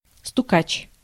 Ääntäminen
Ääntäminen Haettu sana löytyi näillä lähdekielillä: venäjä Käännös Konteksti Ääninäyte Substantiivit 1. informer 2. snitch US 3. stooge 4. dobber 5. grass slangi UK US 6. snoop Translitterointi: stukatš.